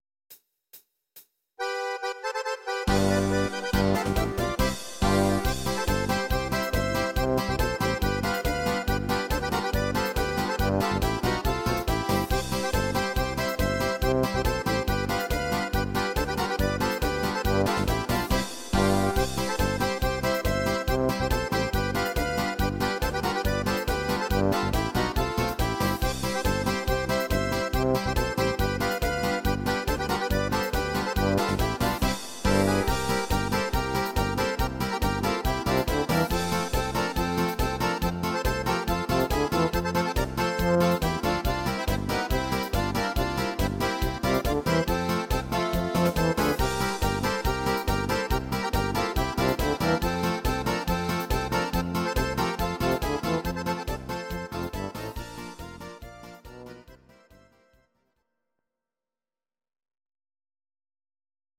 instr. Akkordeon